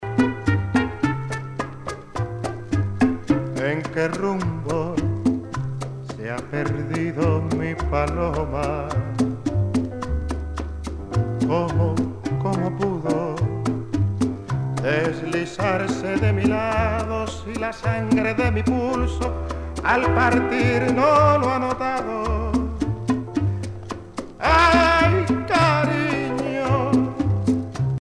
danse : boléro
Pièce musicale éditée